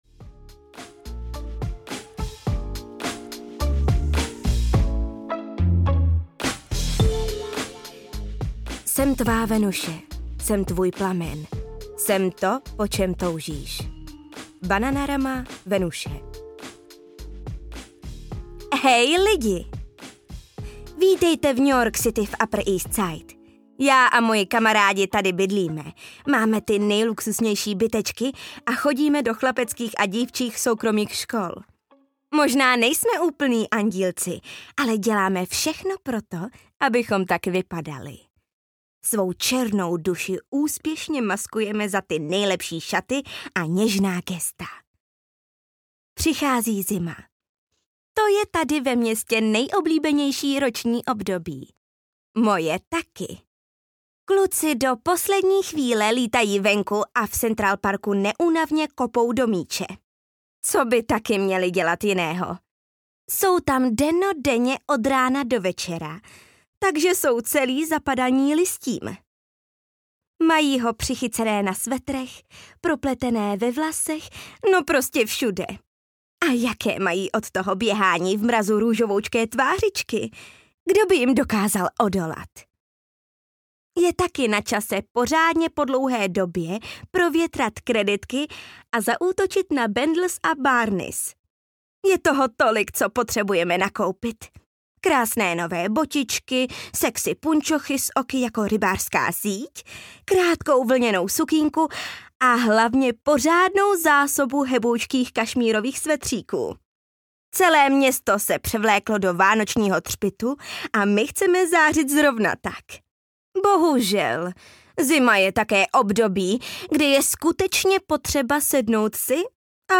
Audio knihaGossip Girl: Ty víš, že mě miluješ
Ukázka z knihy